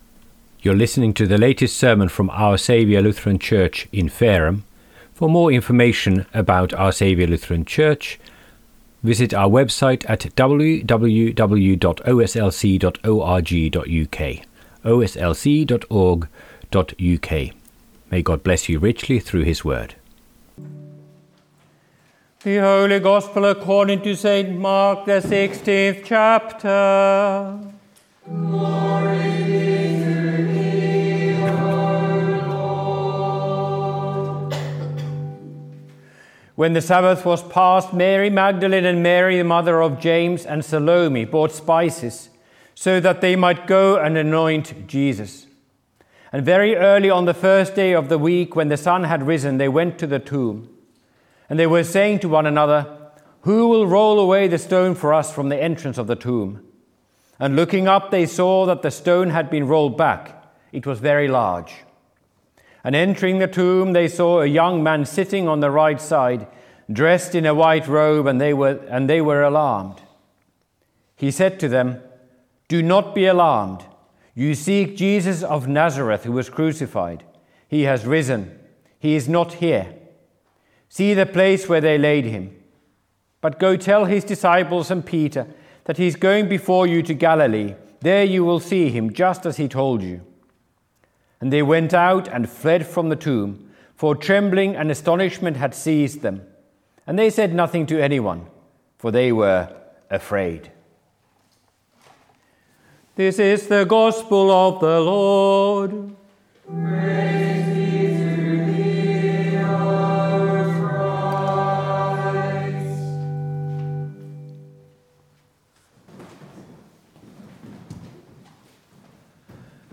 Sermon Recordings - Our Saviour Lutheran Church